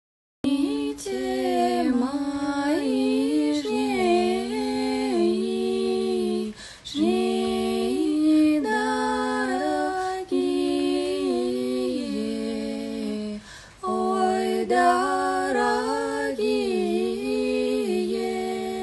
Жен, Вокал/Молодой
Мои демо были записаны на самые разные устройства, чтобы вы могли ознакомиться со звучанием моего голоса.